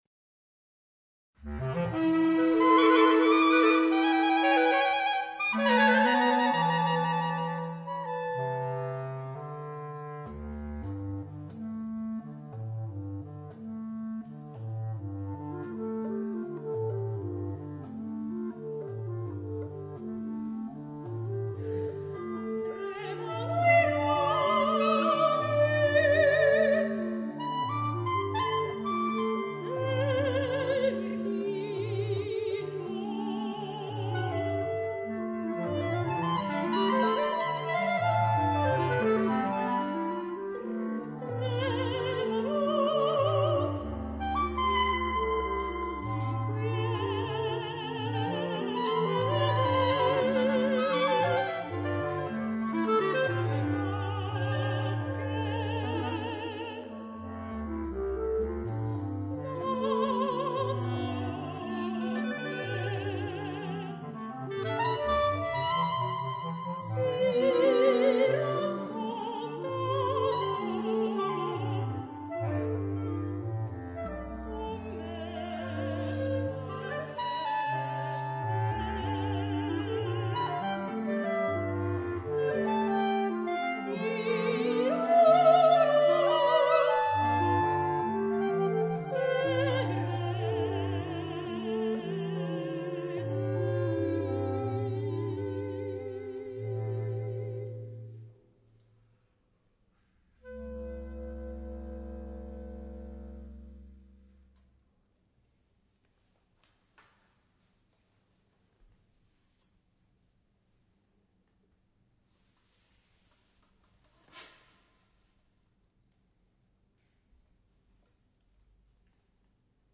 per voce e tre clarinetti